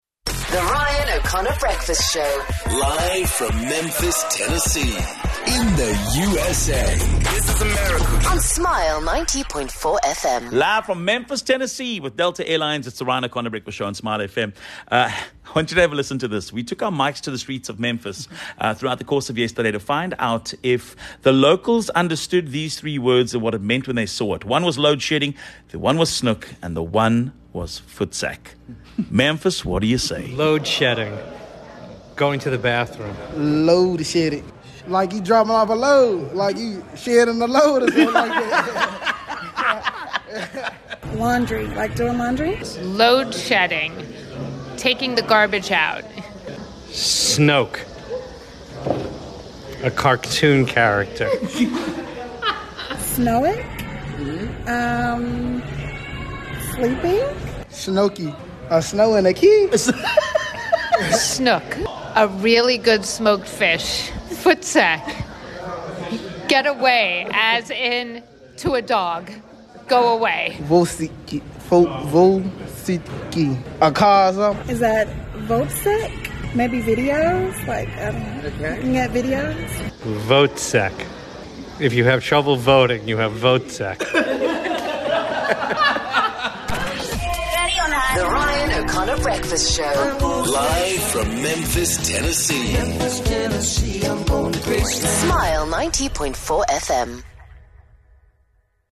As we take in the sights and sounds of Memphis, we also took the opportunity to test locals on what they might know about South Africa. Let's just say that they might not have been informed about certain South African words, we were very entertained.